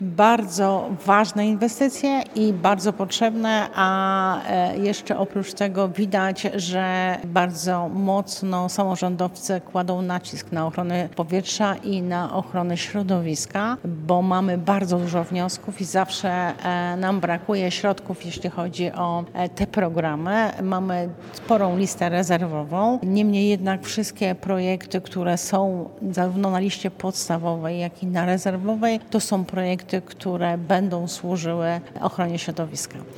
W tym roku samorząd województwa przeznaczył na inwestycje w ramach programu „Mazowsze dla Czystego Powietrza” prawie 7,7 mln złotych – podkreśla członkini zarządu województwa Janina Ewa Orzełowska.